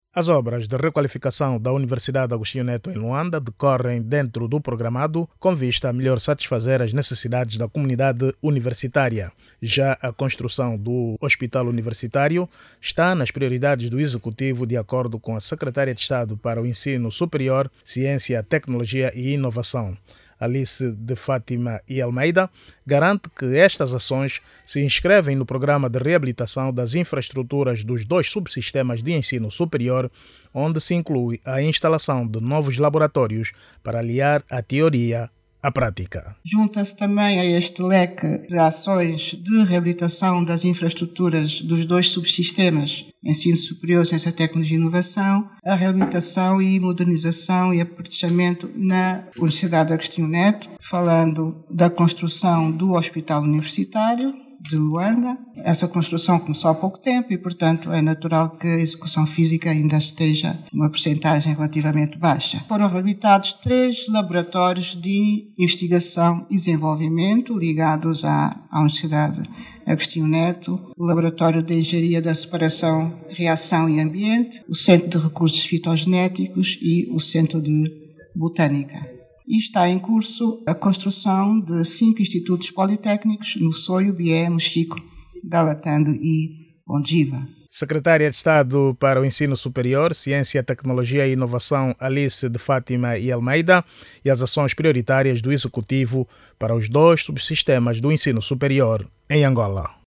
Paralelamente, decorrem as obras de construção do Hospital Universitário de Luanda, destinadas a elevar a capacidade formativa de quadros superiores no ramo da saúde. Jornalista